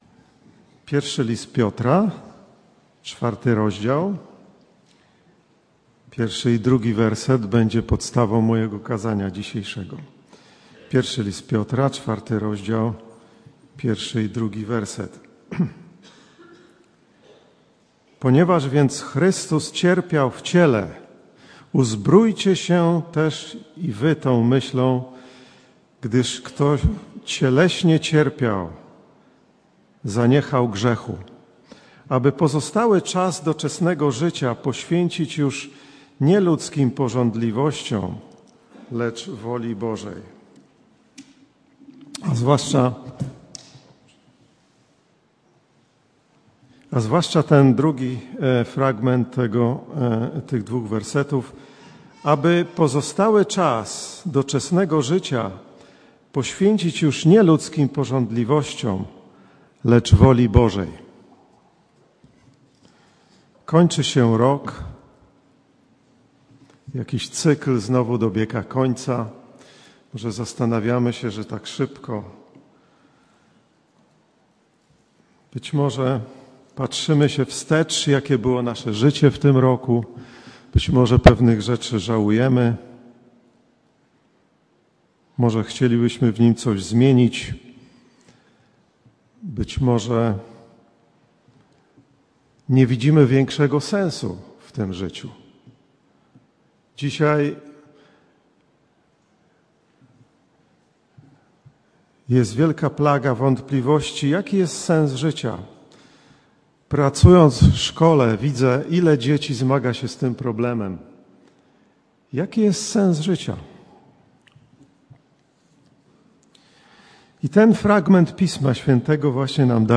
Passage: I List Piotra 4, 1-2 Rodzaj Usługi: Kazanie